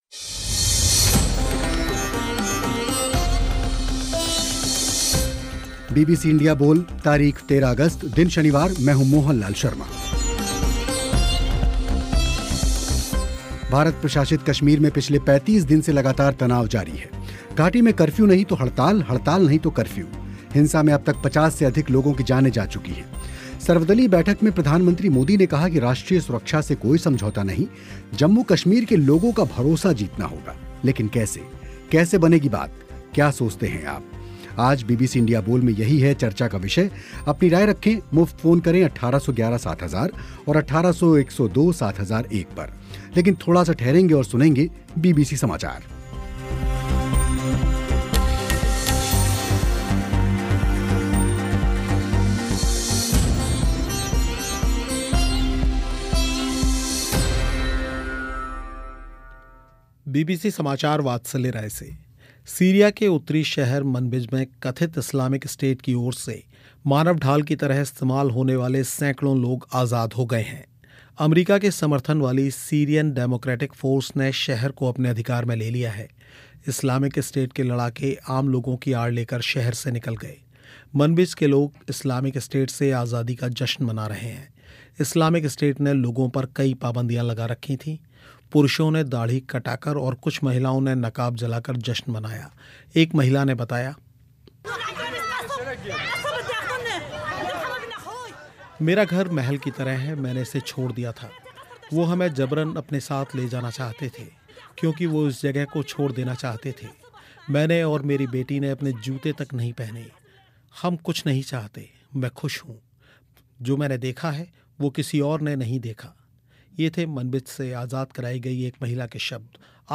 इसी विषय पर हुई चर्चा